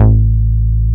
R MOOG A2F.wav